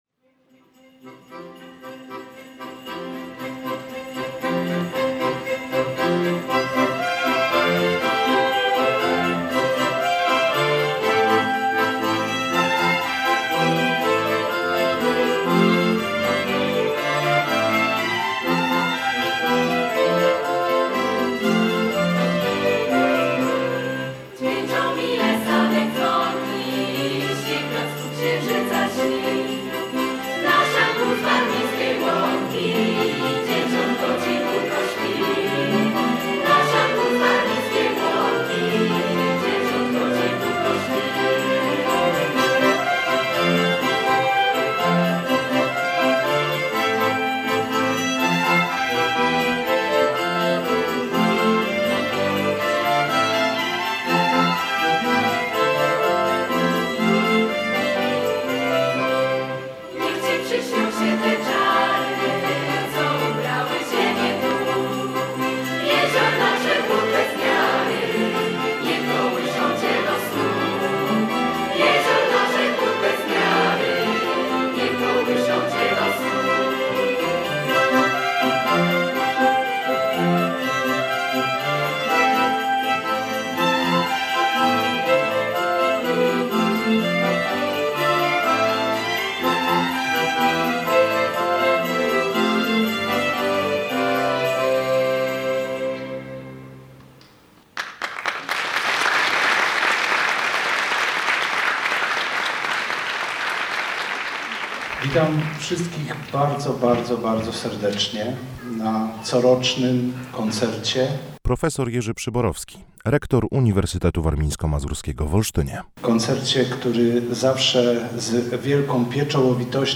W czwartek 11 grudnia ponownie zgromadził on komplet publiczności w głównej auli Centrum Konferencyjnego. Na scenie zaprezentowały się cztery grupy: Chór im. prof Wiktora Wawrzyczka, Zespół Pieśni i Tańca "Kortowo", Studio Wokalne UWM oraz Orkiestra Akademicka UWM. Posłuchajcie naszej radiowej relacji!